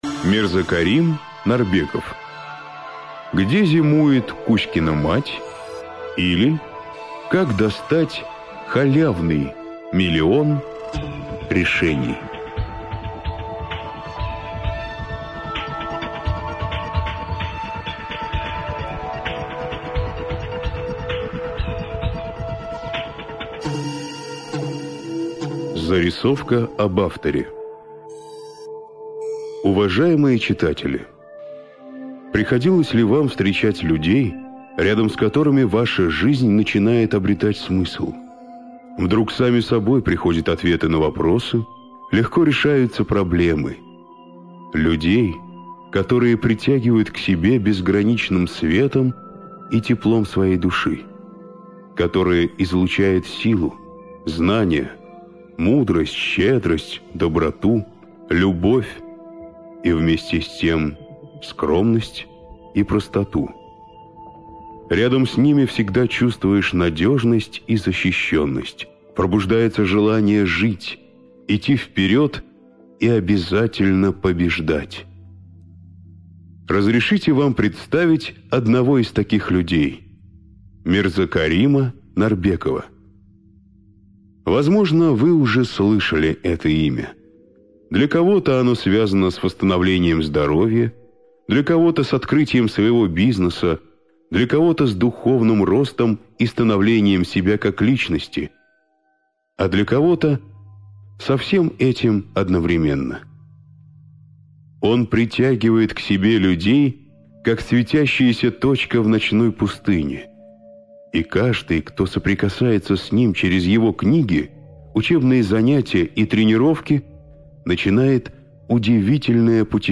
Аудиокнига Где зимует кузькина мать, или Как достать халявный миллион решений | Библиотека аудиокниг